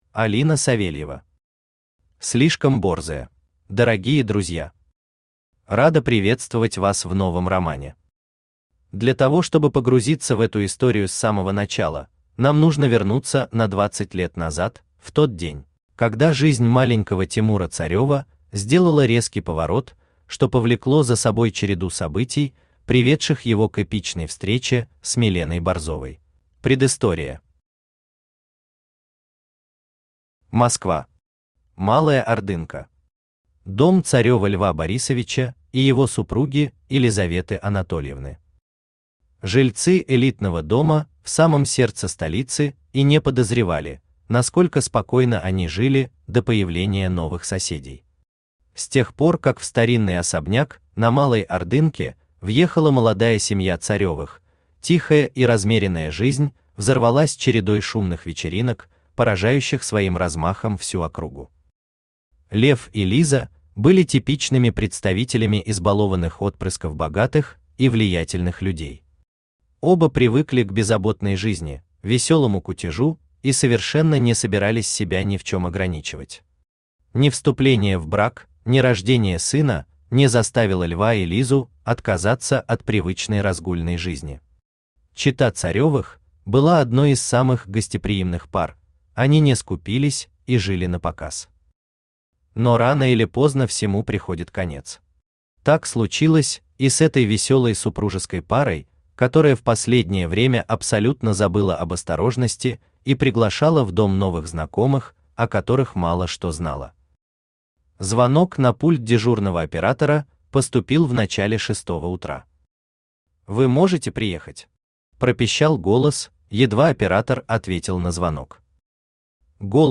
Аудиокнига Слишком борзая | Библиотека аудиокниг
Aудиокнига Слишком борзая Автор Алина Савельева Читает аудиокнигу Авточтец ЛитРес.